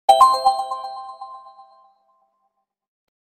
Simple Message Tone Sound Effect
Description: Simple message tone sound effect. Modern message alert sound with a clean, bright tone, perfect for apps, notifications, and mobile devices.
Simple-message-tone-sound-effect.mp3